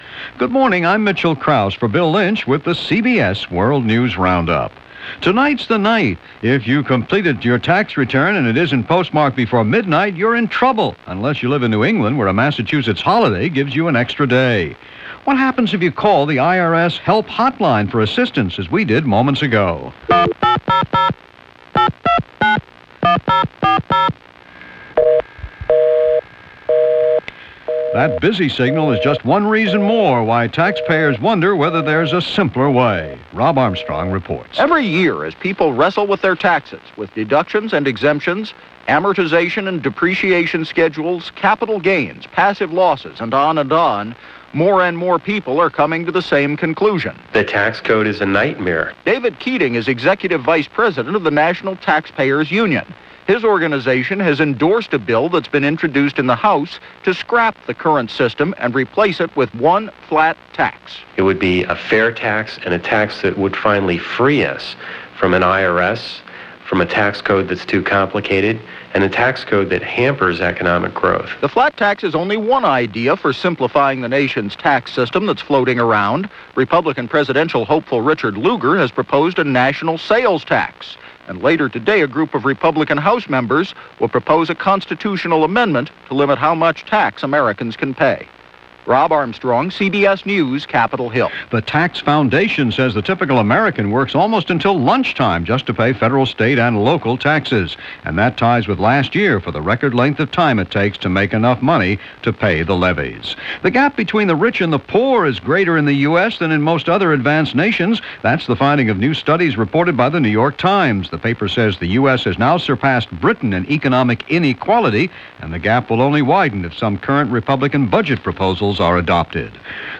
April 17, 1995 – CBS World News Roundup – Gordon Skene Sound Collection –
And that’s just a little of what was happening on this particular Tax Day, April 17, 1995 – as reported by The CBS World News Roundup.